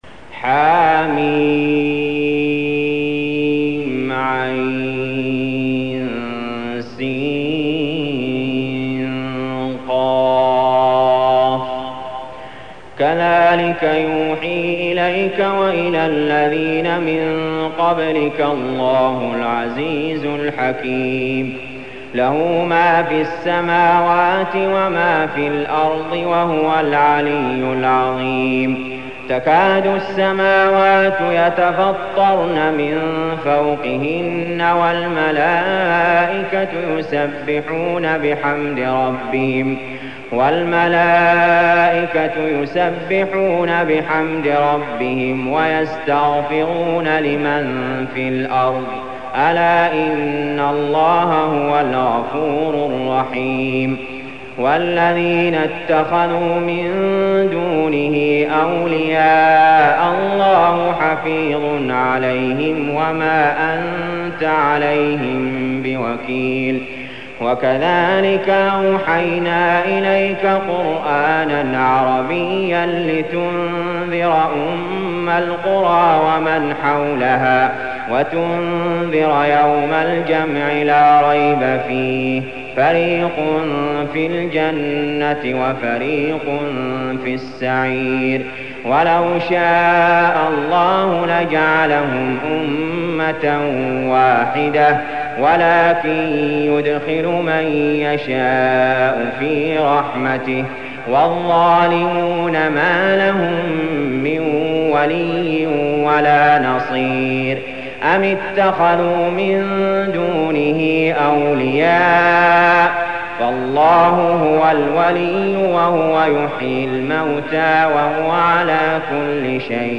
المكان: المسجد الحرام الشيخ: علي جابر رحمه الله علي جابر رحمه الله الشورى